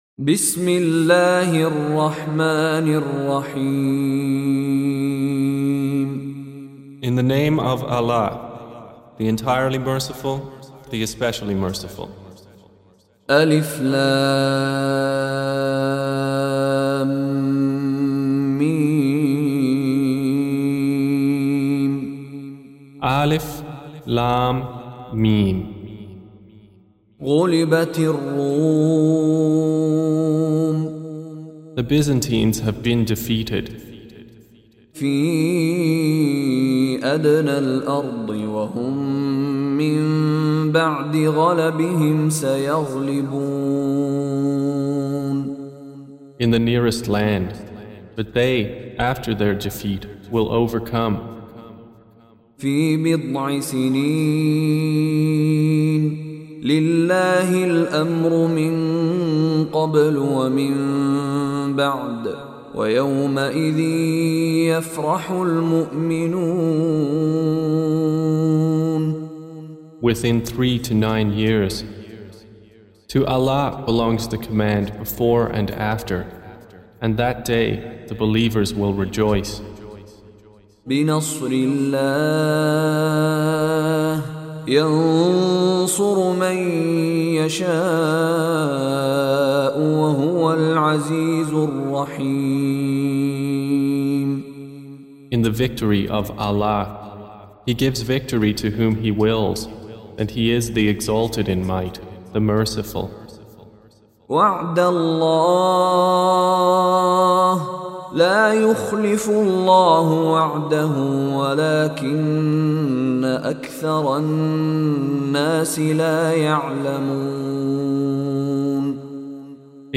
Surah Repeating تكرار السورة Download Surah حمّل السورة Reciting Mutarjamah Translation Audio for 30. Surah Ar�R�m سورة الرّوم N.B *Surah Includes Al-Basmalah Reciters Sequents تتابع التلاوات Reciters Repeats تكرار التلاوات